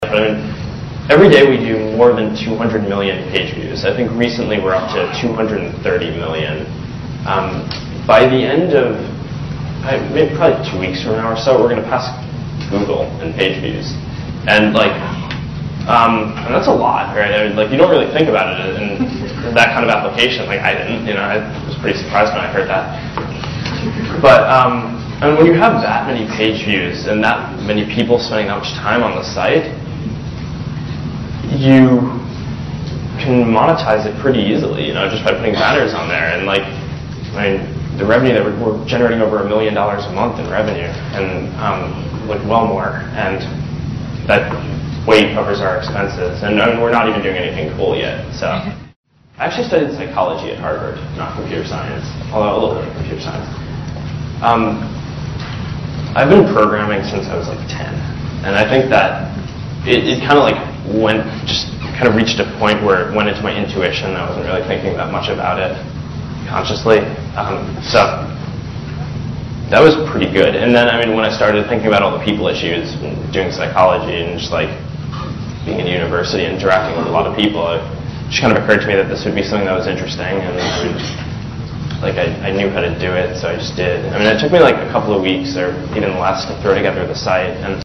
财富精英励志演讲97:确定你的目标(3) 听力文件下载—在线英语听力室